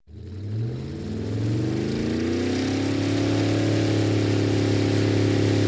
accelerate.ogg